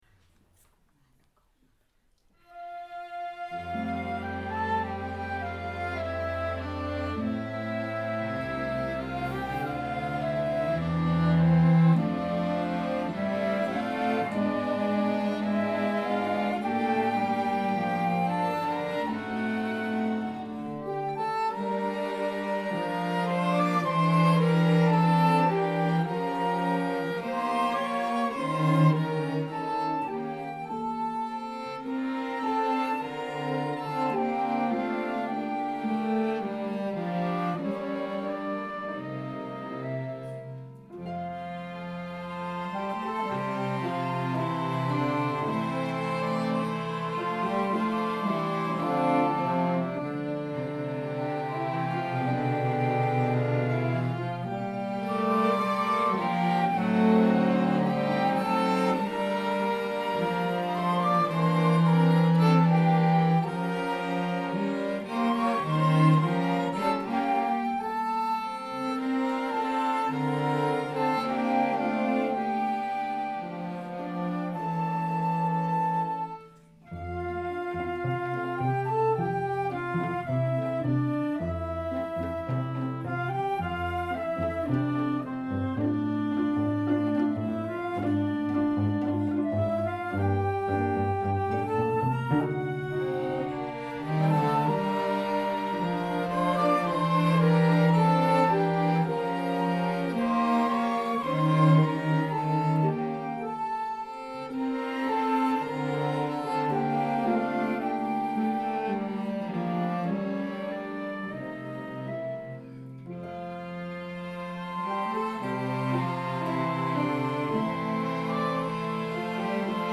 Ein stimmungsvoller Sommerabend und ein klangvolles Konzert des okus rundete die Serenadenwoche 2017 ab.